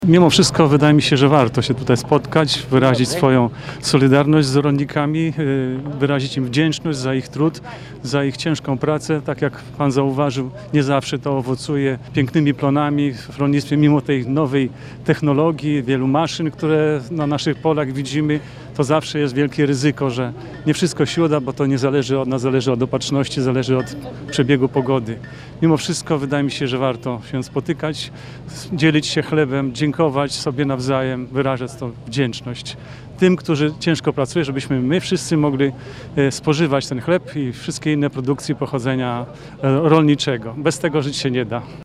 Jak co roku, na terenie Zespołu Szkół w Dowspudzie odbyło się Święto Plonów.
Jak przyznaje starosta, rolnicy nie mają za sobą łatwego roku. Panująca susza zniszczyła wiele upraw, dlatego tym bardziej należą się rolnikom podziękowania za ich ciężką pracę.